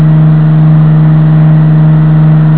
flaps.wav